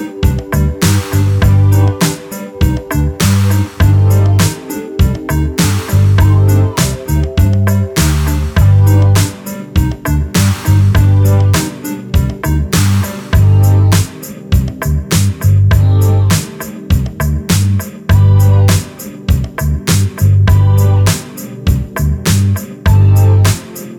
no Backing Vocals Reggae 3:33 Buy £1.50